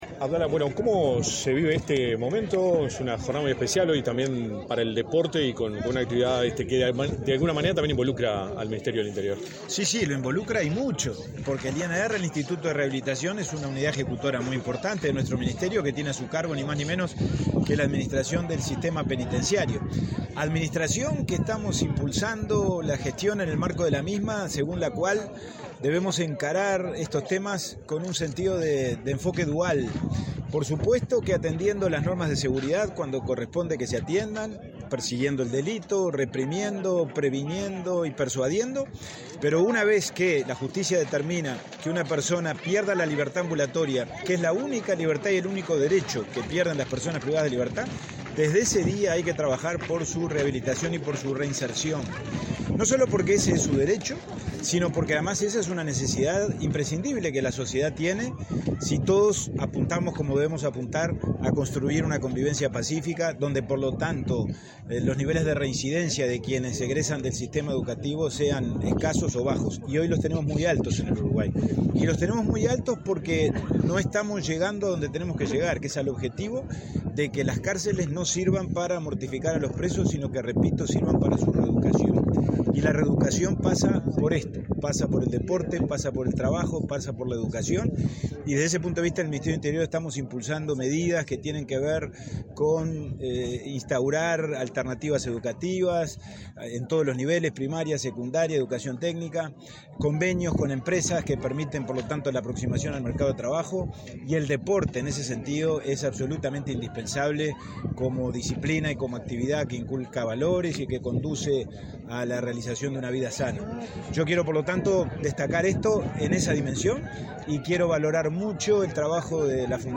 Declaraciones a la prensa del ministro interino del Interior, Pablo Abdala
Luego, el ministro interino del Interior, Pablo Abdala, realizó declaraciones a la prensa.